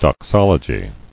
[dox·ol·o·gy]